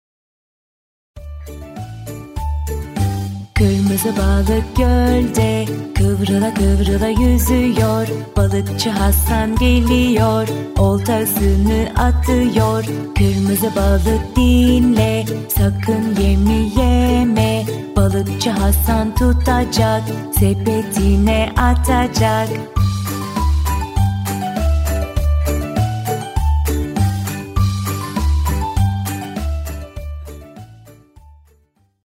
Cinsiyet: Kadın